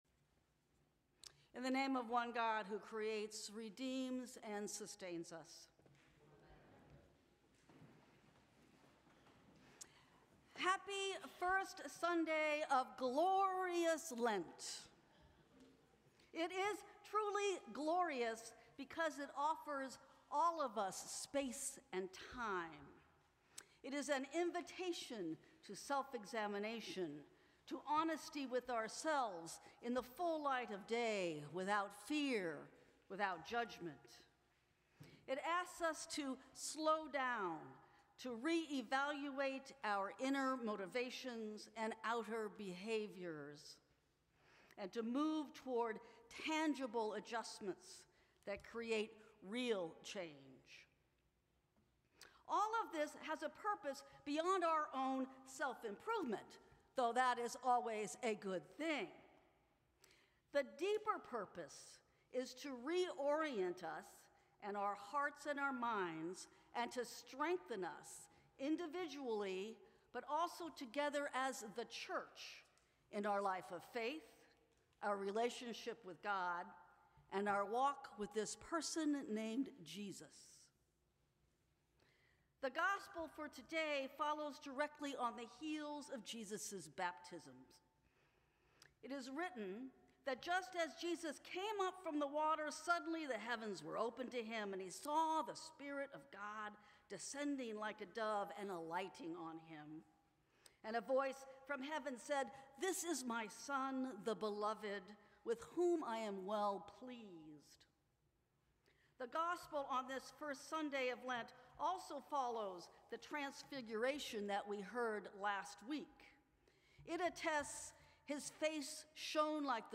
Sermons from St. Cross Episcopal Church